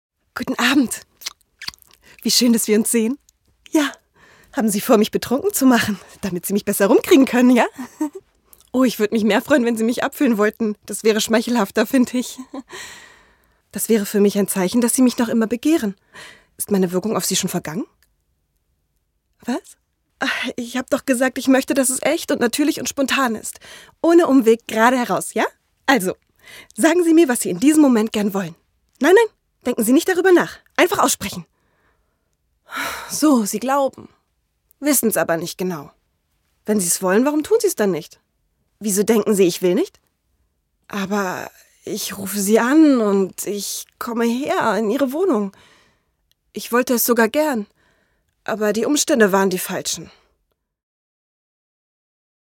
aufgebracht Synchron - Komödie VoiceOver